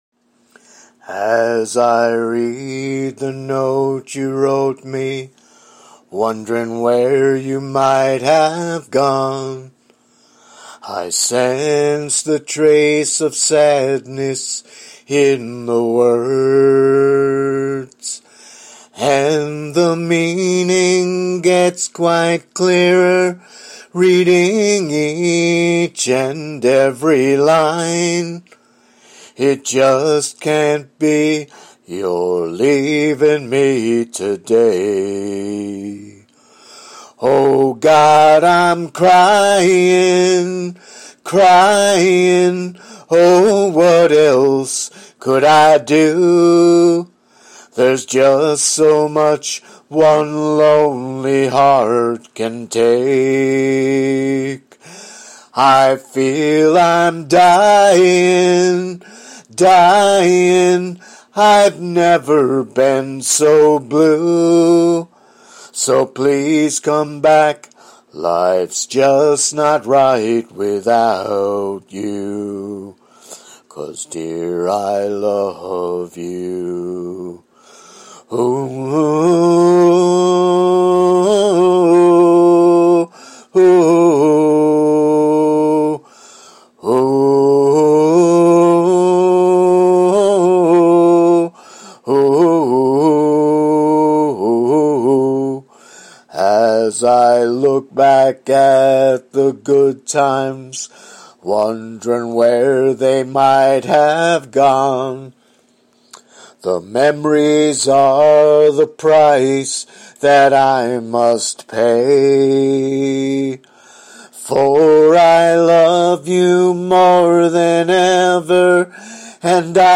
5 Non-Christian, Country-Style Songs…
all are sung “a cappella” (voice only)